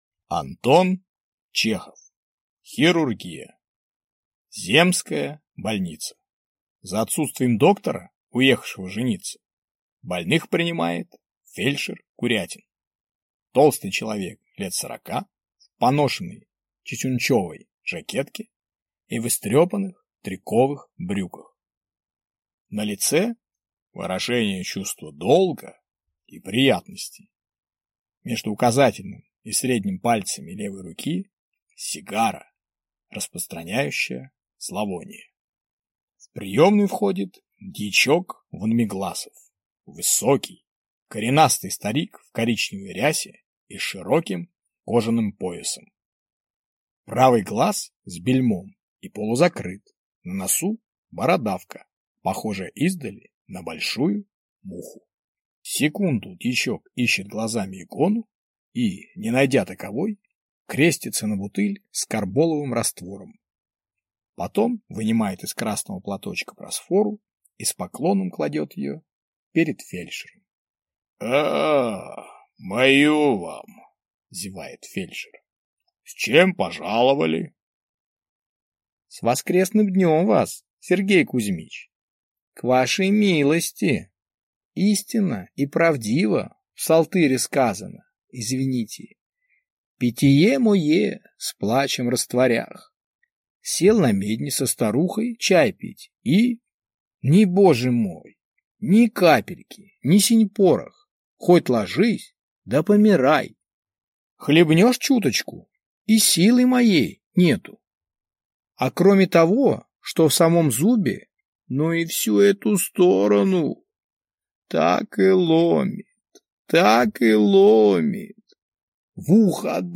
Аудиокнига Хирургия | Библиотека аудиокниг